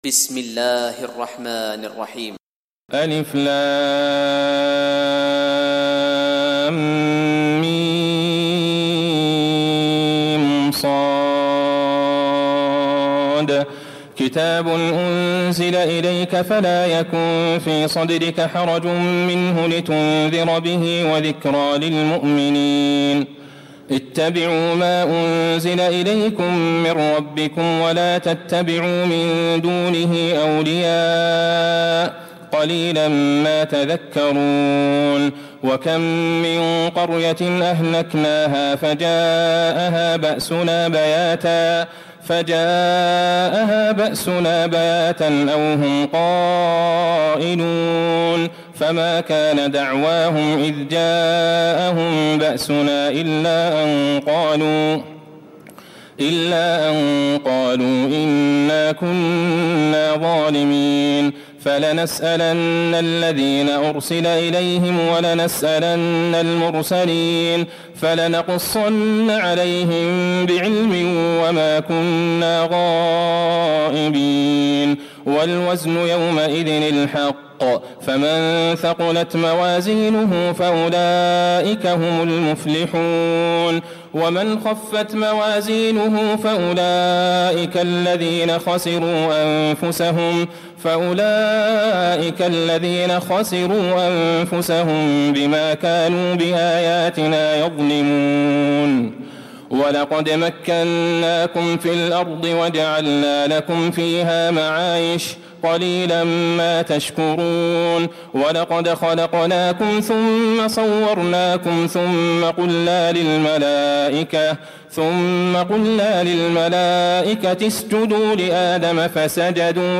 تراويح الليلة الثامنة رمضان 1436هـ من سورة الأعراف (1-93) Taraweeh 8 st night Ramadan 1436H from Surah Al-A’raf > تراويح الحرم النبوي عام 1436 🕌 > التراويح - تلاوات الحرمين